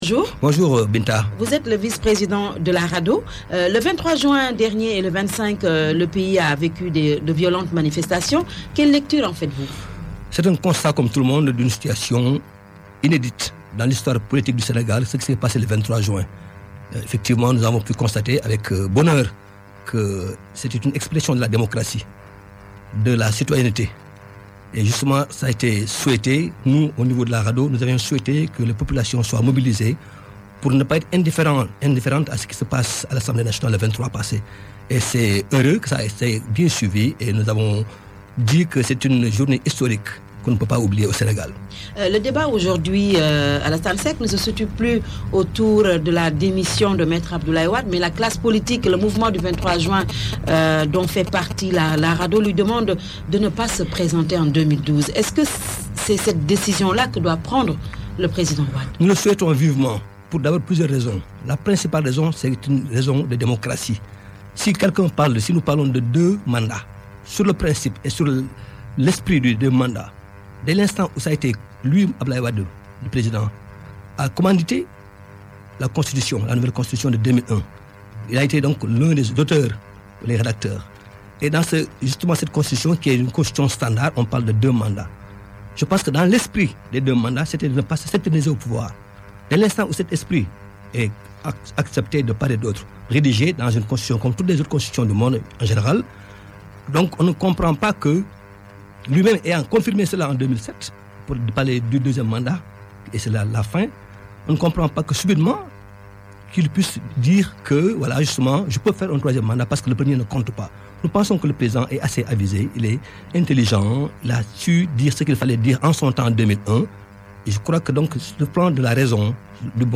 L'interview de la matinale